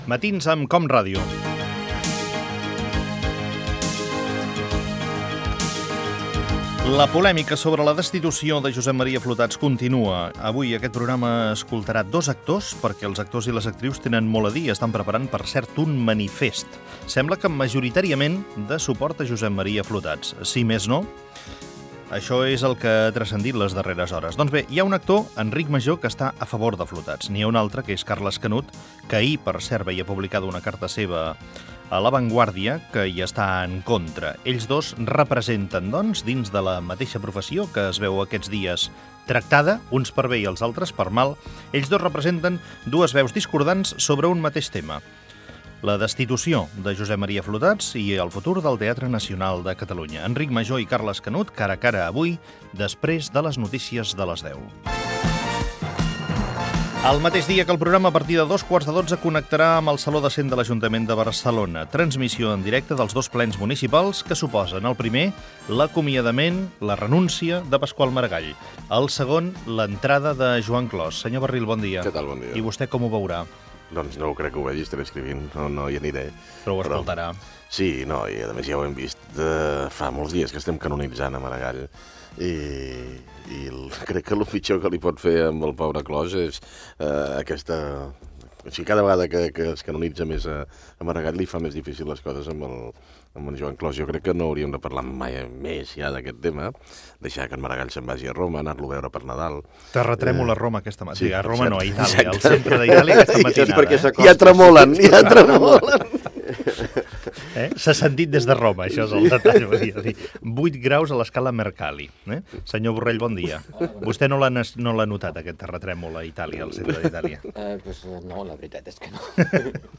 Identificació del programa, sumari de continguts i fragment de la tertúlia amb Joan Barril, Josep Borrell i Josep Ramoneda.
Info-entreteniment
Fragment extret de l'arxiu sonor de COM Ràdio.